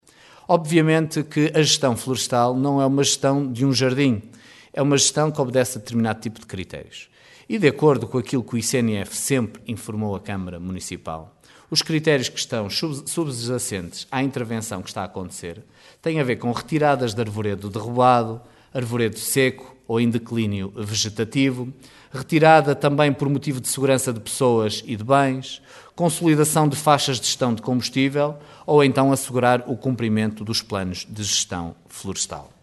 Opinião bem distinta manifestou o presidente da Câmara de Caminha, Miguel Alves que diz confiar no ICNF e nos seus profissionais, um organismo que considera ser bastante rigoroso.